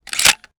weapon_foley_drop_25.wav